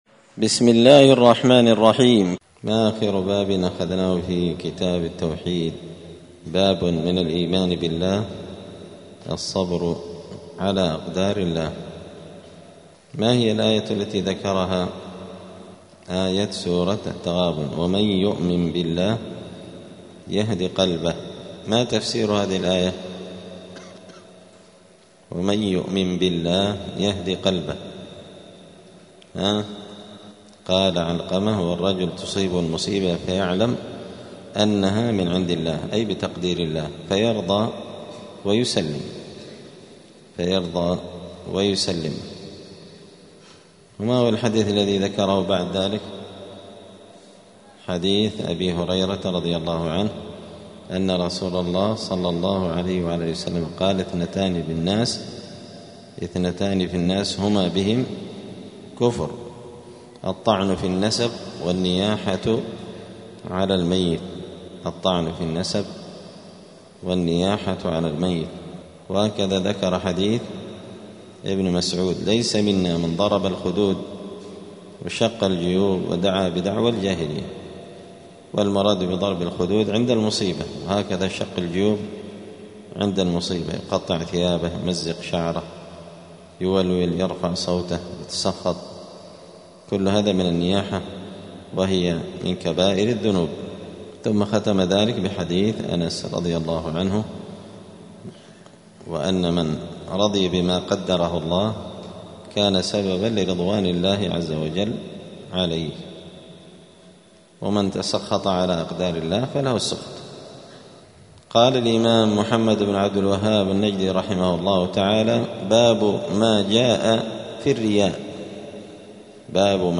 دار الحديث السلفية بمسجد الفرقان قشن المهرة اليمن
*الدرس المائة (100) {باب الرياء}*